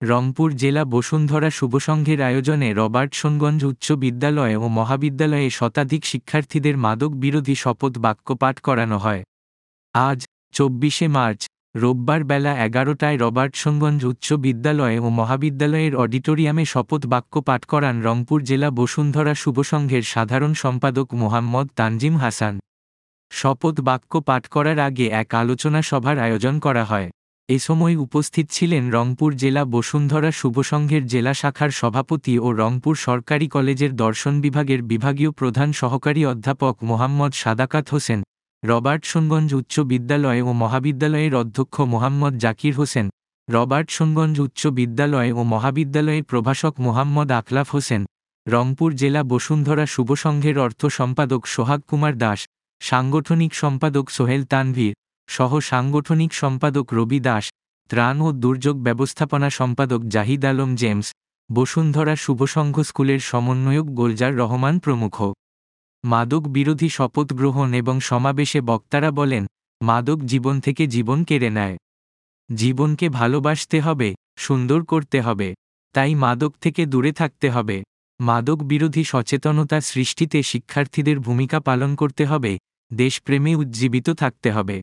মাদক বিরোধী শপথ গ্রহণ এবং সমাবেশে বক্তারা বলেন, মাদক জীবন থেকে জীবন কেড়ে নেয়।